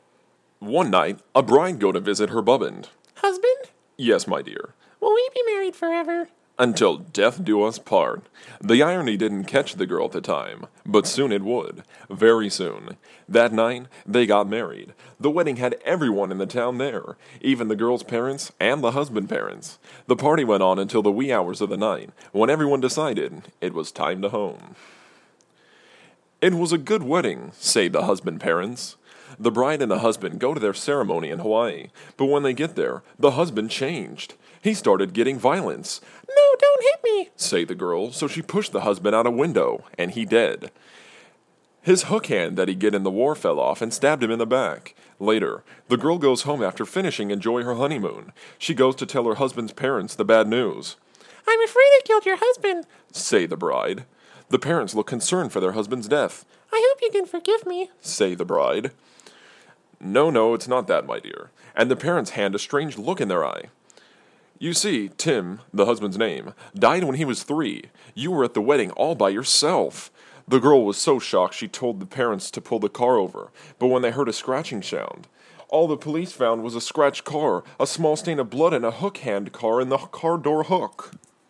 Intentionally bad creepypasta
I did over 9,000 takes on this because it is written really messed up and I kept laughing.